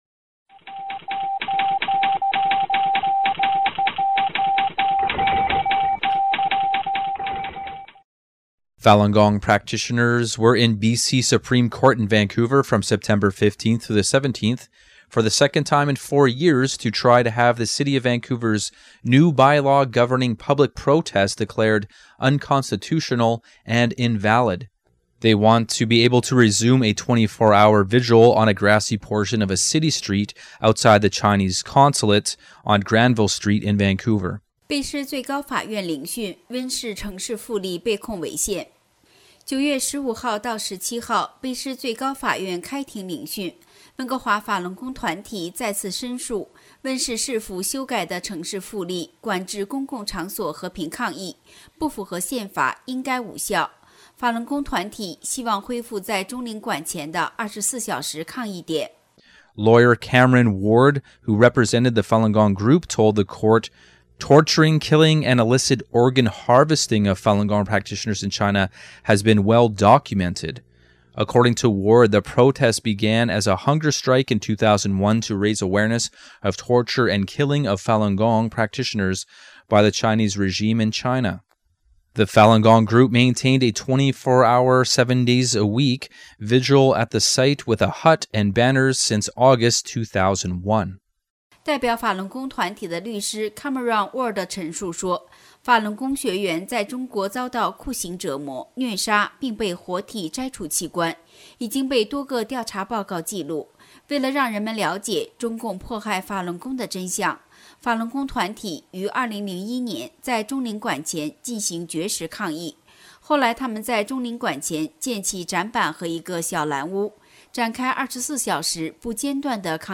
Bilingual China-related weekly news
128kbps Mono